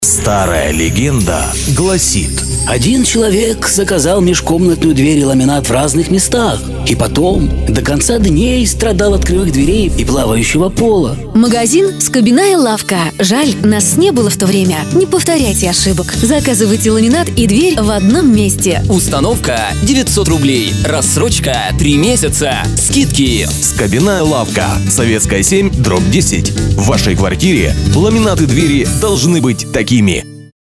Радиоролик магазина дверей и ламината (сценарий) Категория: Копирайтинг